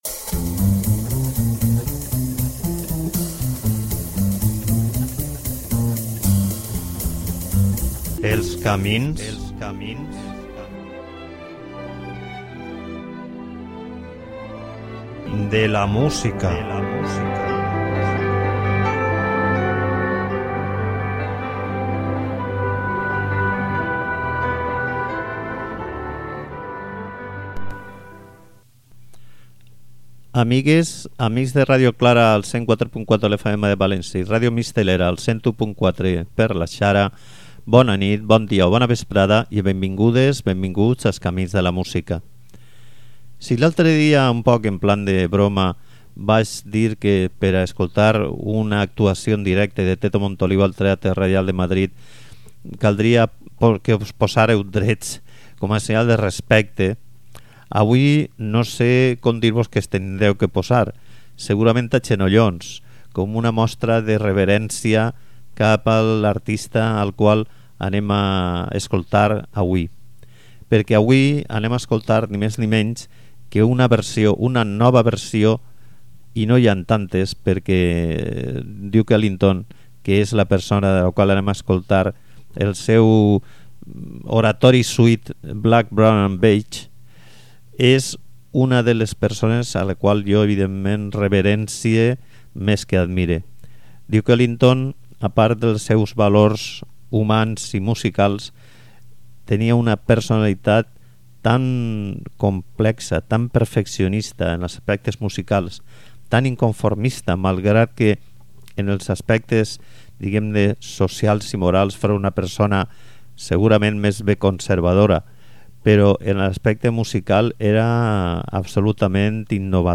suite